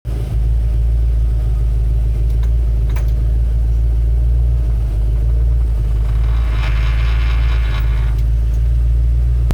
NP435 Noise
Went to drive it in the shop and received this noise after putting it in first and releasing the clutch.
Didn't give it any gas but same noise (this is the recording).
Sounds like the noise is coming from the transmission and not the gvod.
I know it sounds like the gears aren't meshing but I can't think of what I'd have done to cause this.